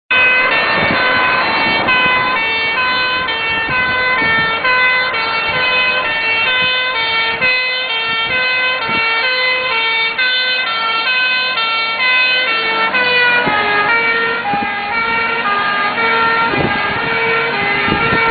feuerwehr.mp3